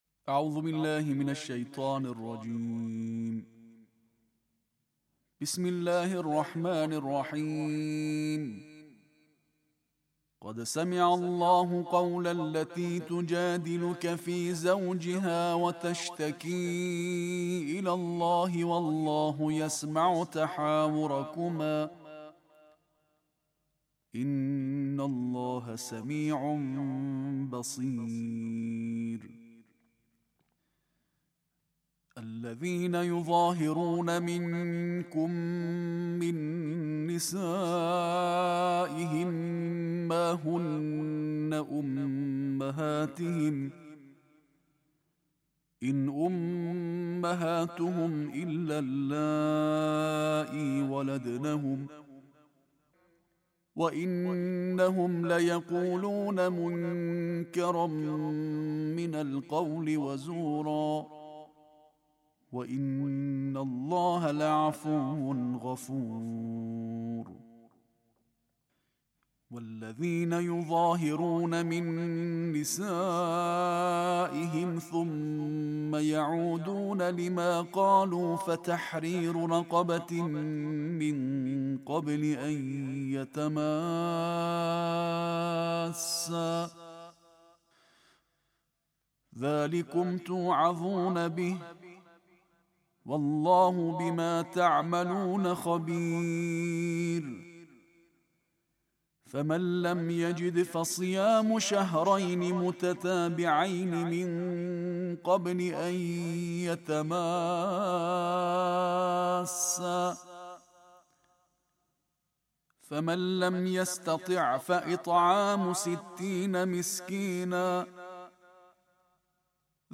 Récitation en tarteel de la 28e partie du Coran